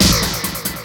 Snare Drum 67-09.wav